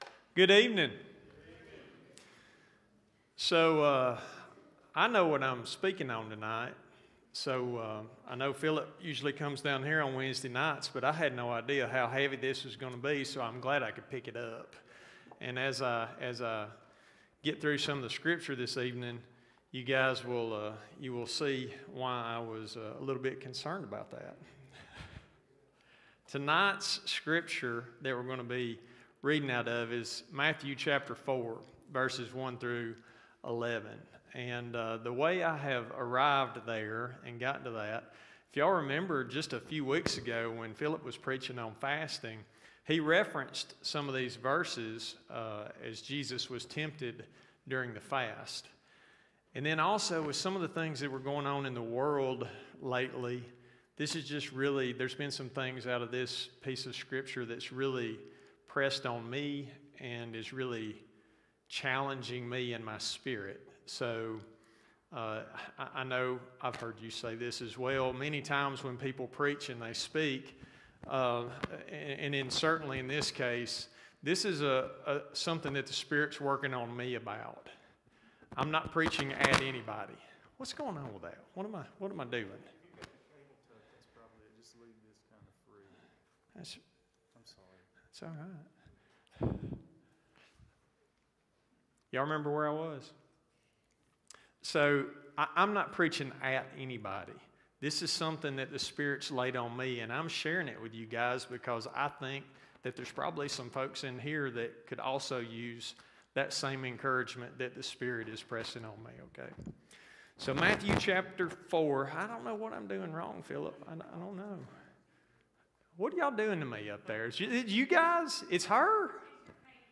Wednesday night sermon.